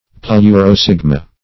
Search Result for " pleurosigma" : The Collaborative International Dictionary of English v.0.48: Pleurosigma \Pleu`ro*sig"ma\, n. [NL.
pleurosigma.mp3